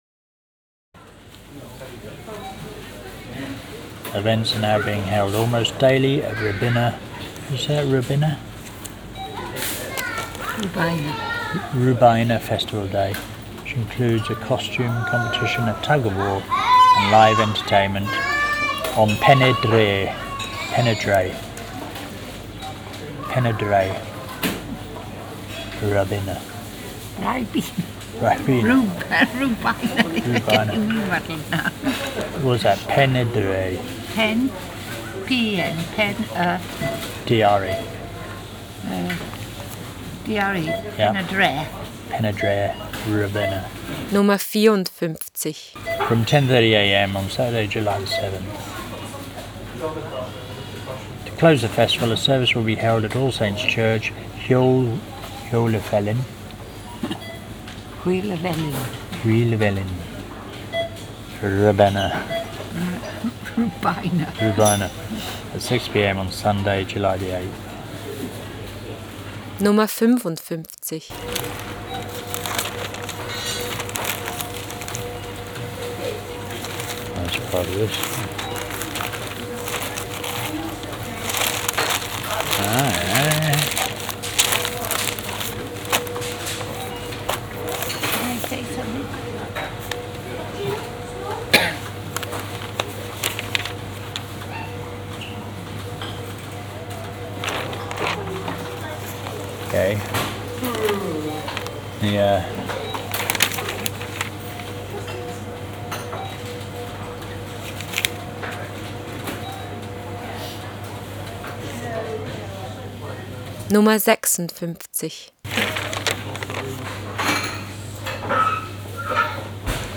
This is an excerpt of The Barry Echo, a site-specific sound piece for the Pfänder Gondola in the Alpine-town of Bregenz, Austria, commissioned by the Bregenz Kunstverein, Magazine 4.
It was continually played through the tannoy system of the gondola as it made its passage up and down the mountain in the Summer of 2007.